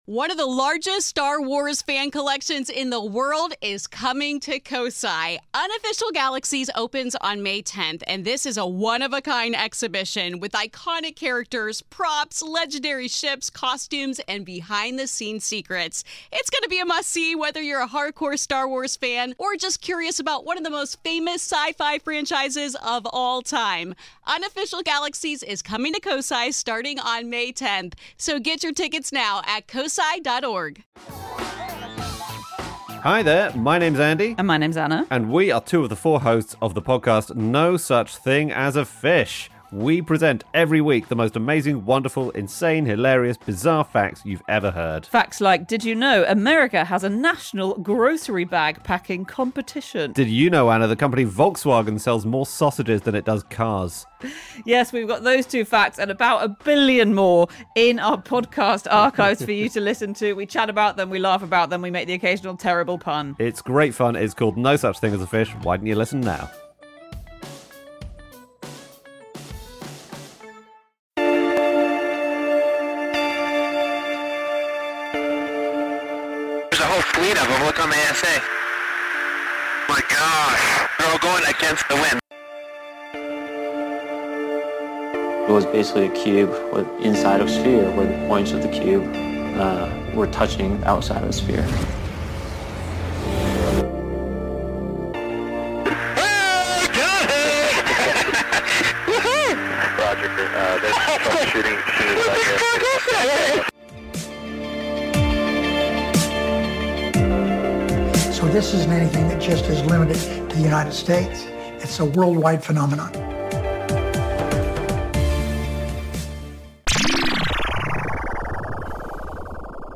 This is the first in a series of preview interviews for guests & speakers who will be appearing at this years Contact In The Desert expo!